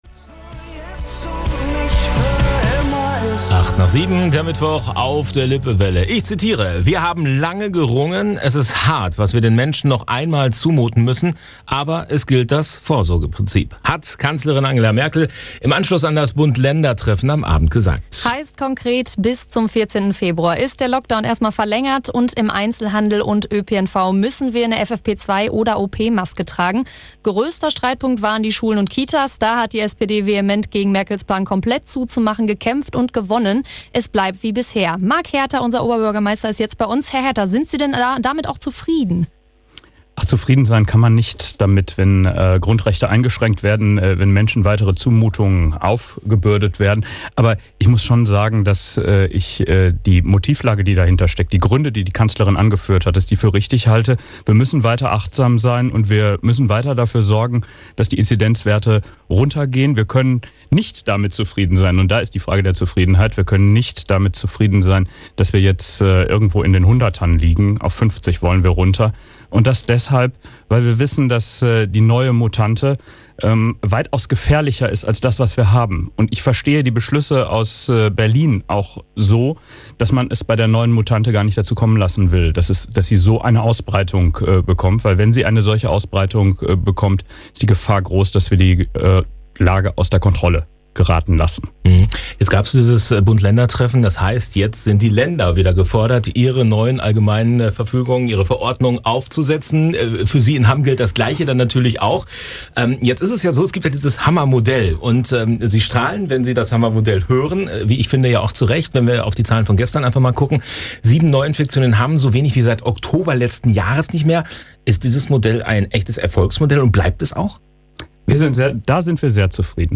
Was davon auch für Hamm gilt, erzählt Oberbürgermeister Marc Herter im Interview.